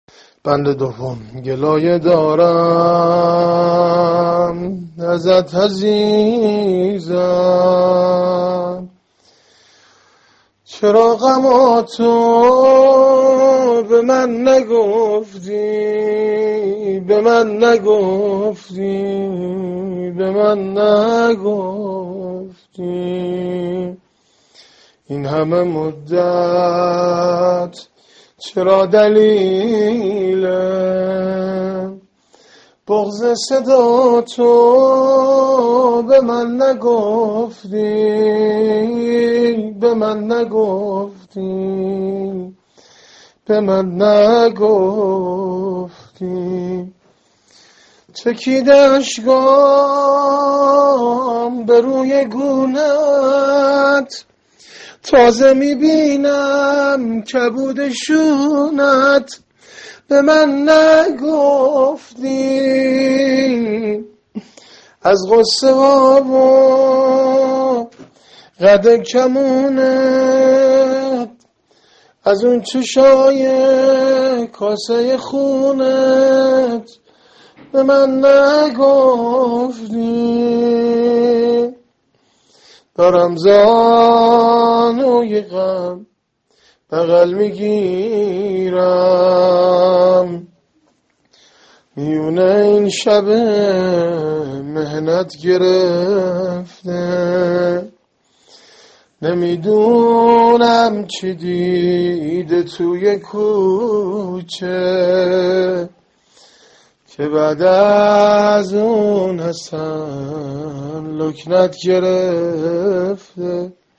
زمزمه ایام فاطمیه -(می بینی خانوم ، پاشیده از هم ، یه خونواده‌، (فاطمه جانم)(۳))